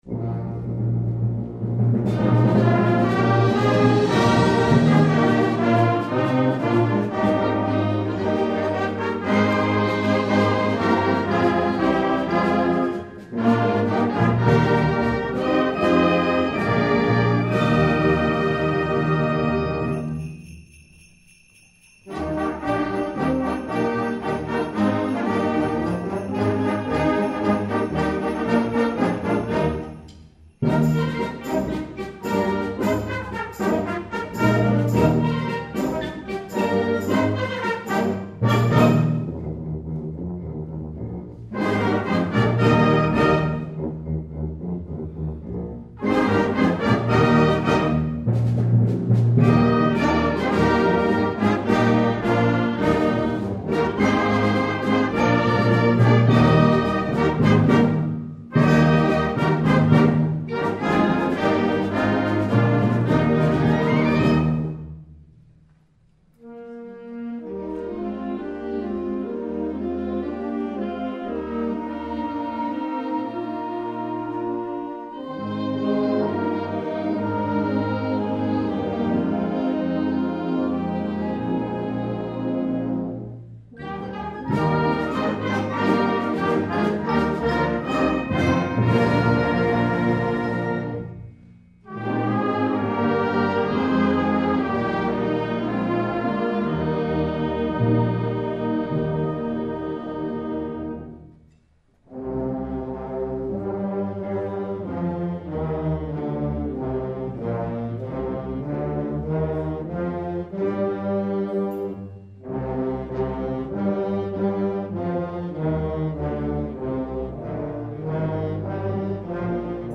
2009 Winter Concert
FLUTE
OBOE
CLARINET
ALTO SAXOPHONE
TRUMPET
TROMBONE
TUBA
PERCUSSION